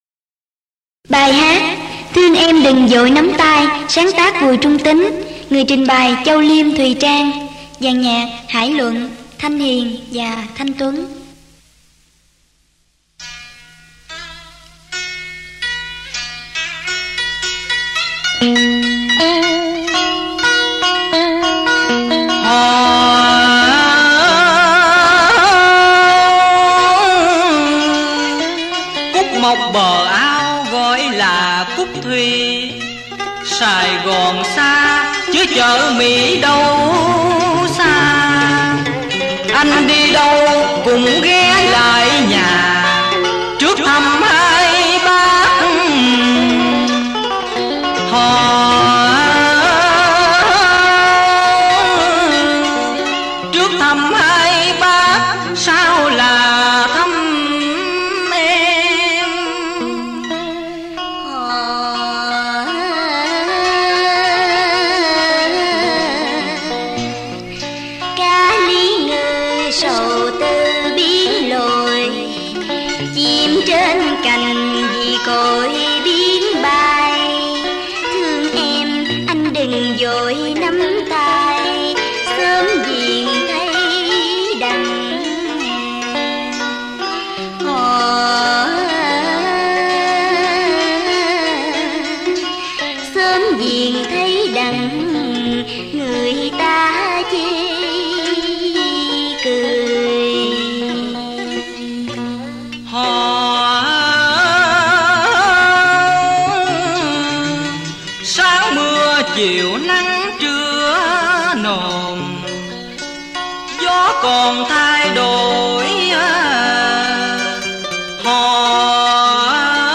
Thương em đừng vội nắm tay (vọng cổ
ca sĩ nhạc dân tộc